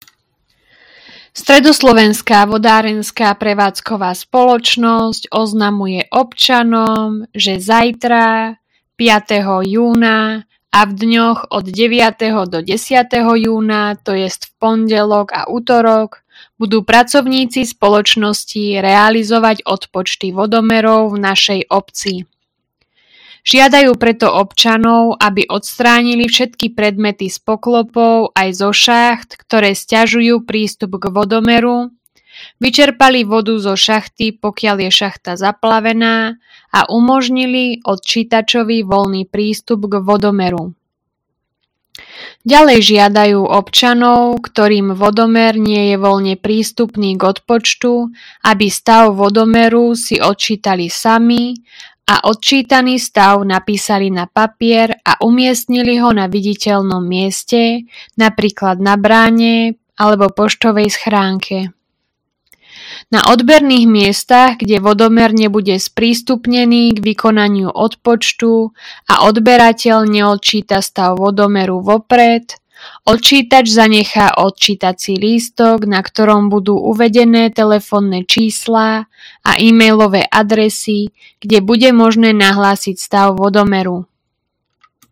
Hlásenie obecného rozhlasu – Odpočet vodomerov